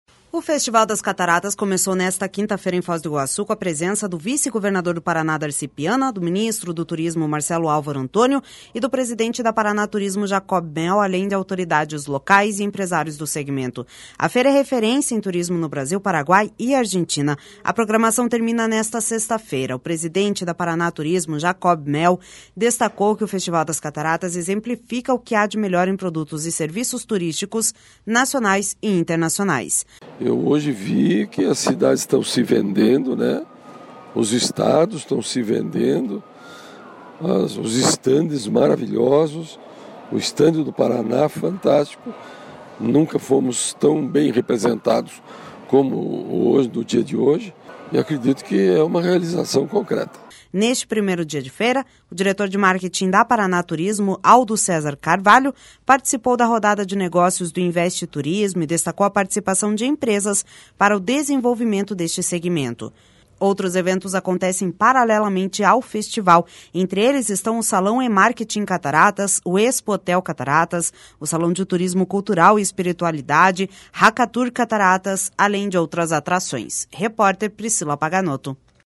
O presidente da Paraná Turismo, Jacob Mehl, destacou que o Festival das Cataratas exemplifica o que há de melhor em produtos e serviços turísticos nacionais e internacionais.// SONORA JACOB MEHL//